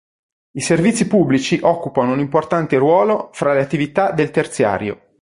/terˈt͡sja.rjo/